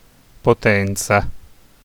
Potenza (/pəˈtɛnzə/, also US: /pˈtɛntsɑː/;[3][4] Italian: [poˈtɛntsa]
It-Potenza.ogg.mp3